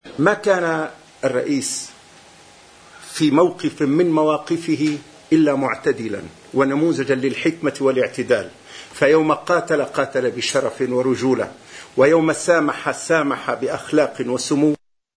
استقبل الرئيس ميشال عون مفتي سورية احمد بدر الدين حسون في بعبدا، الذي قال بعد اللقاء: ما كان الرئيس عون بموقف من مواقفه الا معتدلاً، ونموذجاً للحكمة والإعتدال.